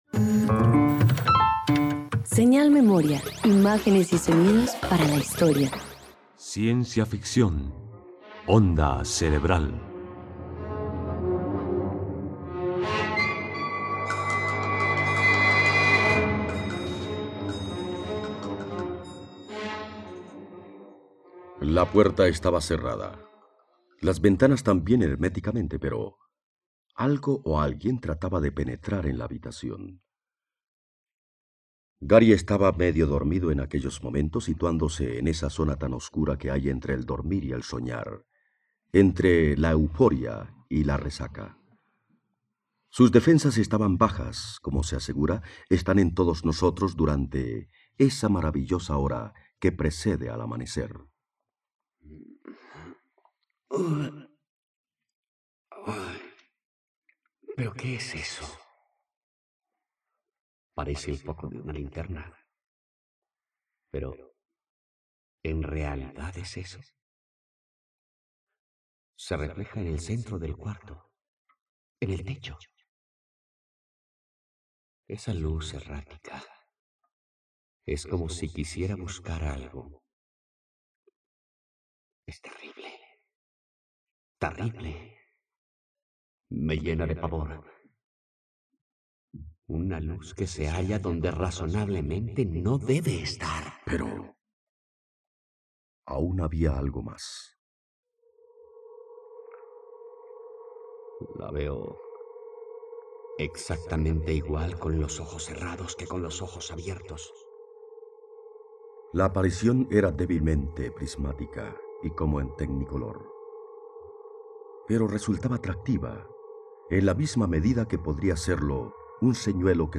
En medio de una visita interplanetaria que lo llena de dudas, Gary trata de encontrar una respuesta lógica a los intentos de comunicación que ha presenciado. Una adaptación radiofónica de la obra de ciencia ficción original de Samuel Palmer.